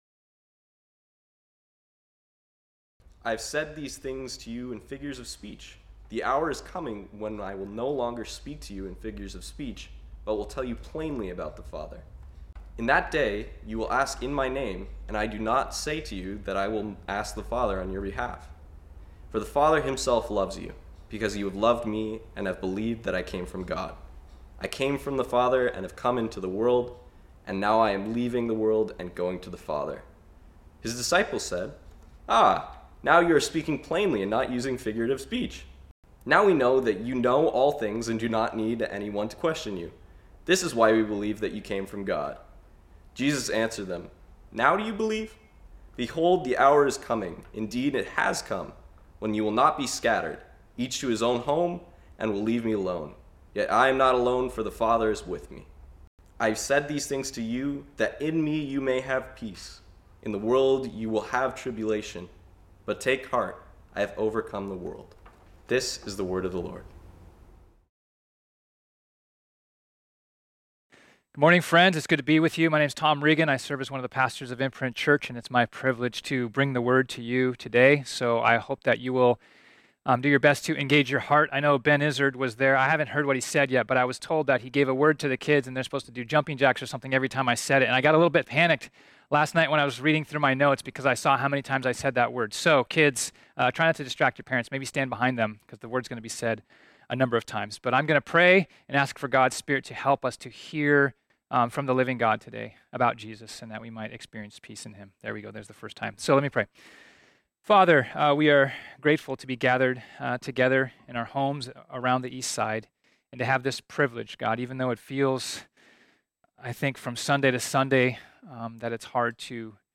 This sermon was originally preached on Sunday, June 28, 2020.